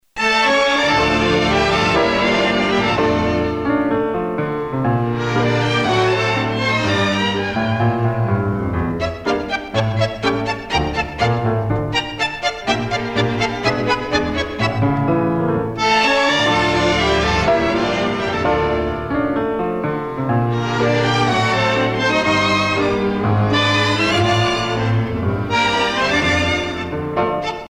tango
Pièce musicale éditée